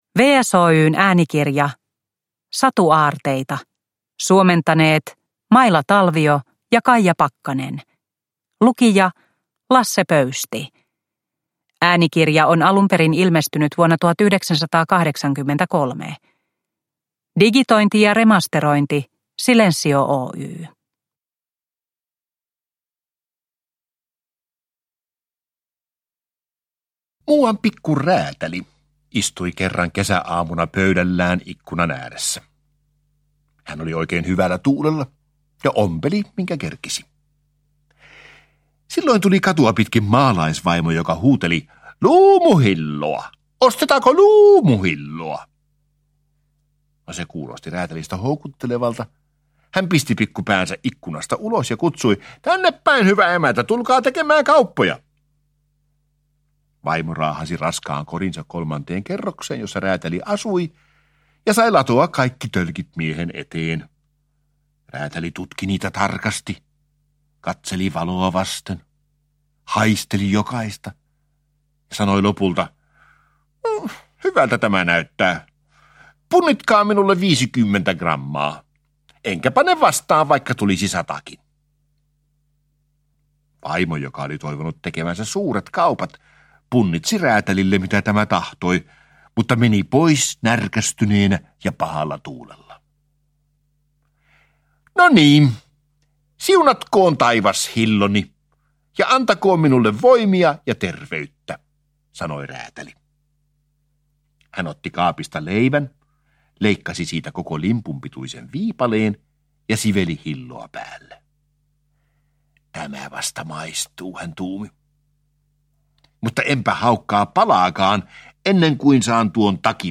Lasse Pöystin lukemia satuklassikoita.
Satuaarteita sisältää Grimmin veljesten sadun Uljaasta räätälistä, H. C. Andersenin sadun Satakieli sekä eskimosadun Navarana ja jättiläinen. Sadunkertojamestari Lasse Pöysti eläytyy tarinoiden taianomaiseen maailmaan.
Uppläsare: Lasse Pöysti